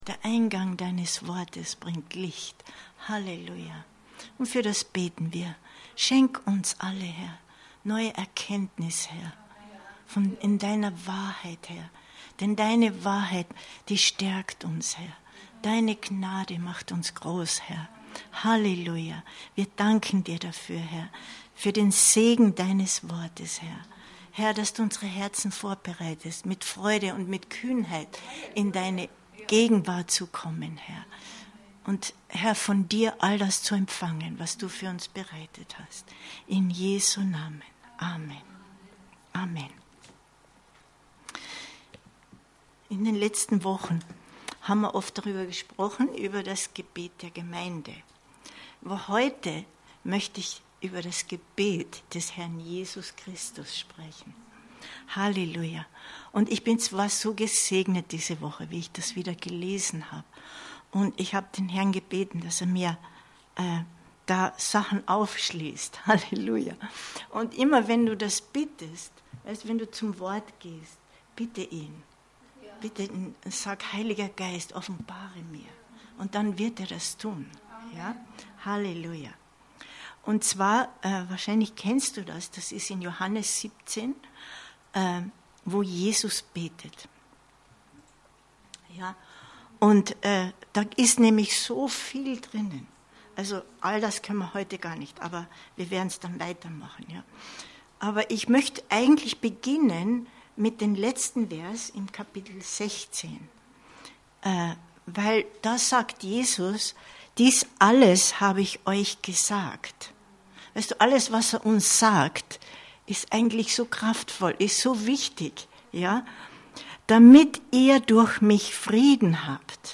Aufnahme des Bibelabends vom Mittwoch, 17.08.2022